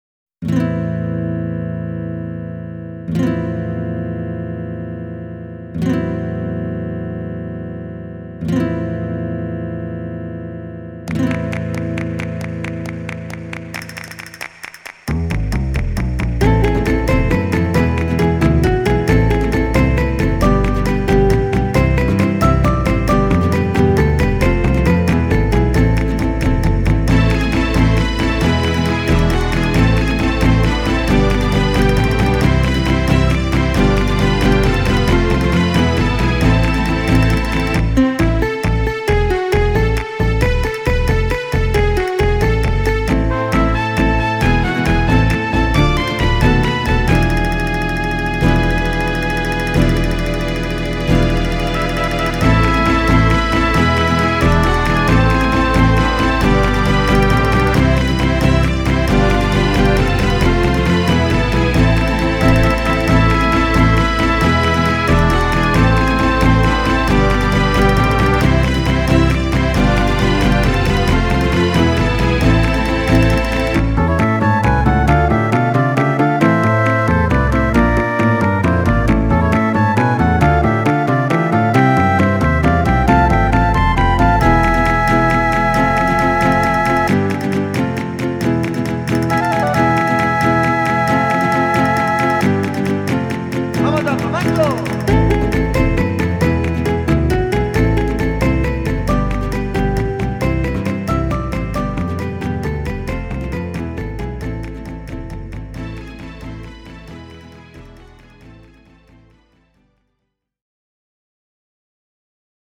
Coda, hard finish with a flair and all.